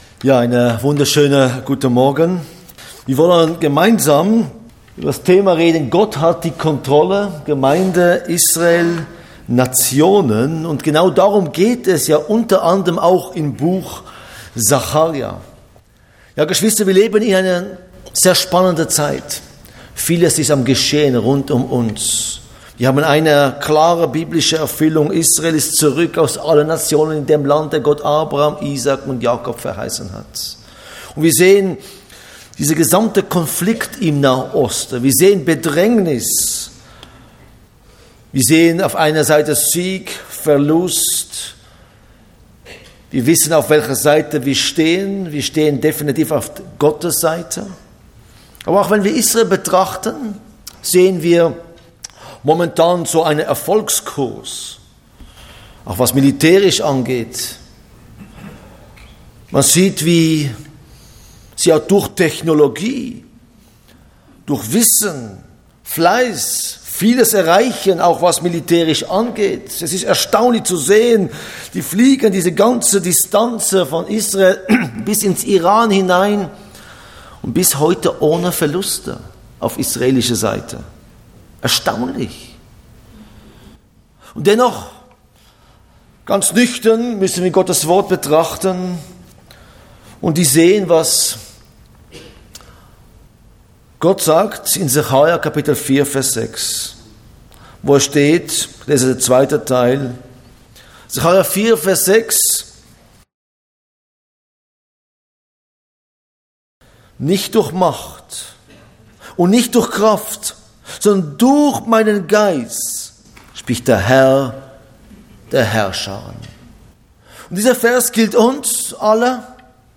Botschaft Zionshalle https